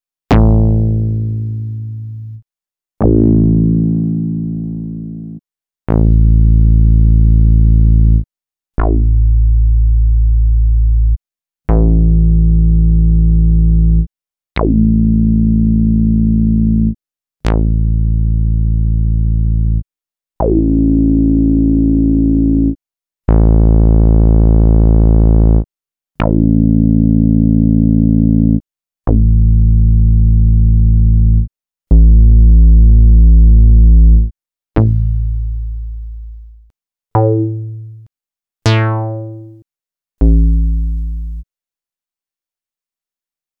13_SolidBass.wav